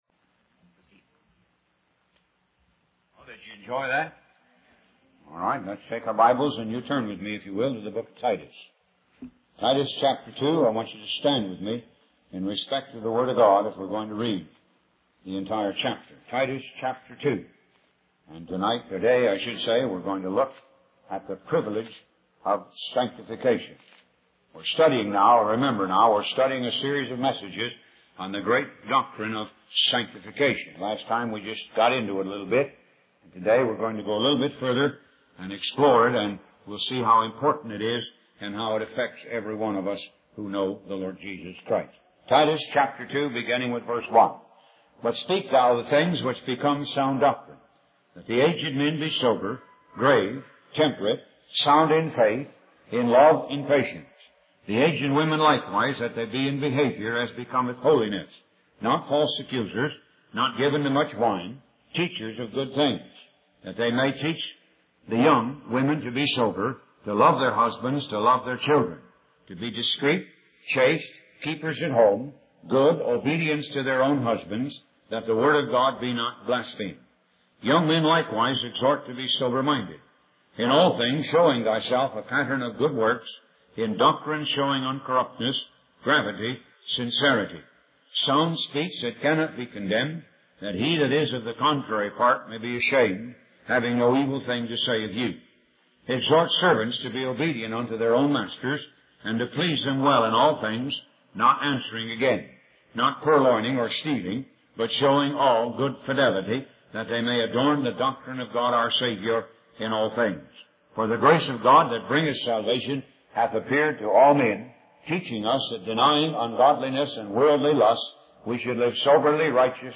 Talk Show Episode, Audio Podcast, Moga - Mercies of God Association and The Privilege of Sanctification on , show guests , about The Privilege of Sanctification, categorized as Health & Lifestyle,History,Love & Relationships,Philosophy,Psychology,Christianity,Inspirational,Motivational,Society and Culture